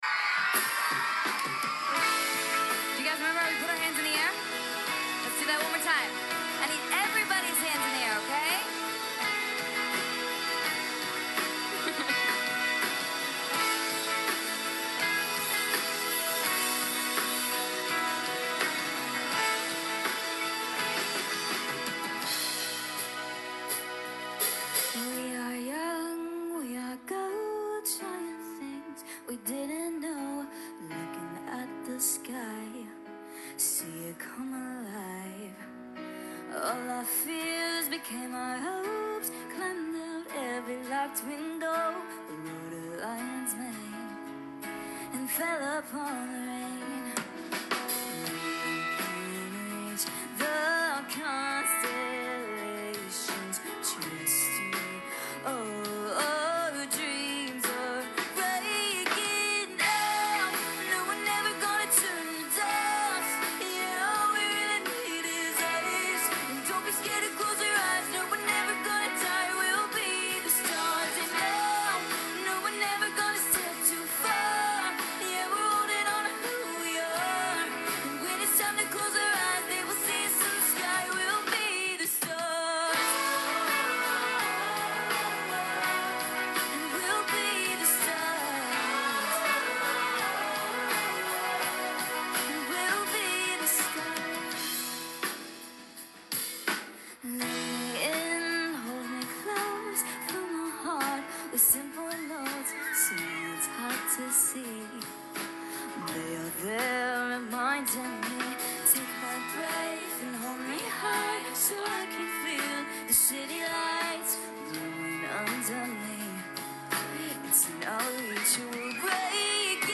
live at YTV summer beach bash II